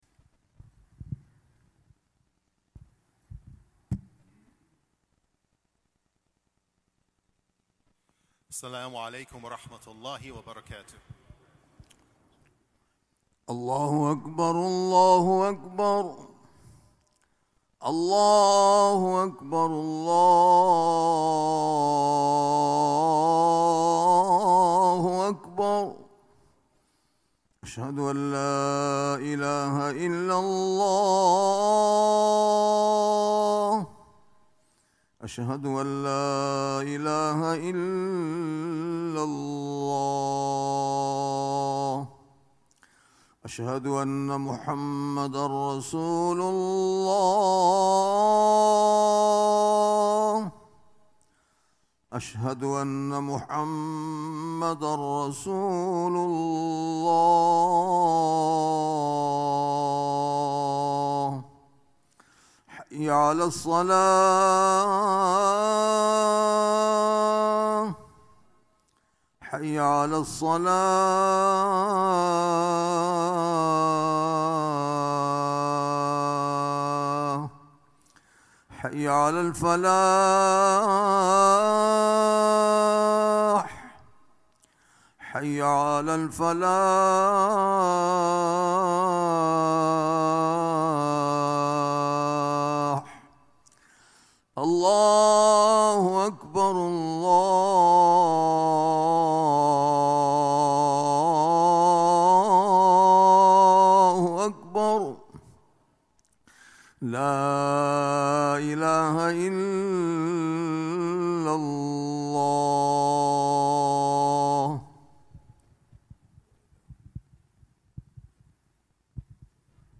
Friday Khutbah - "Soul Control"